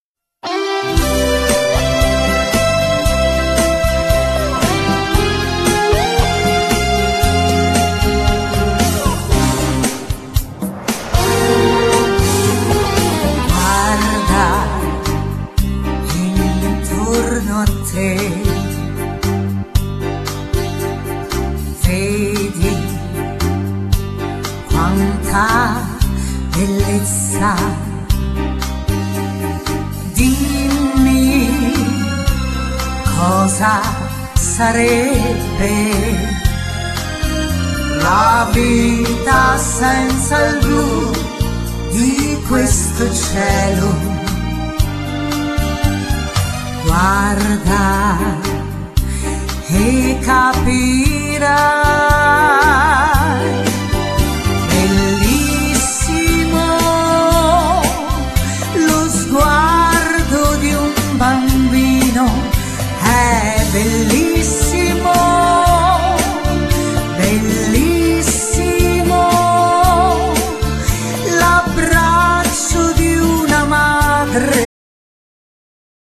Genere : Liscio